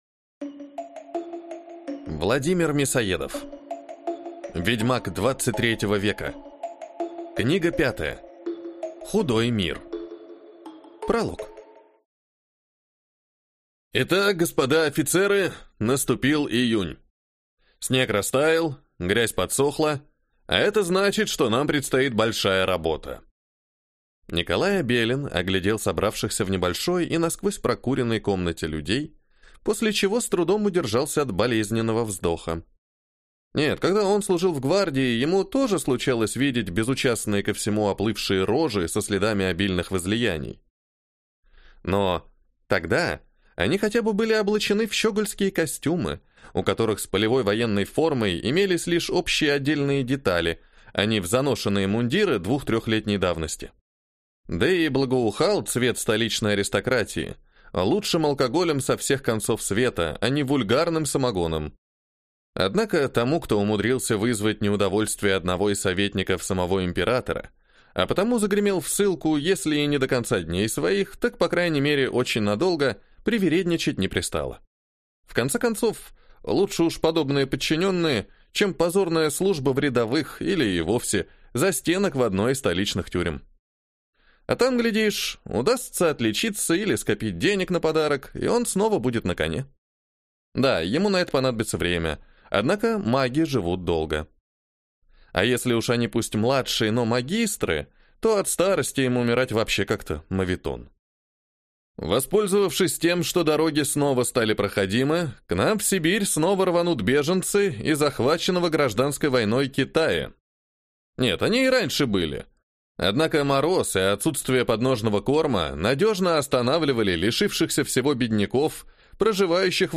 Aудиокнига Худой мир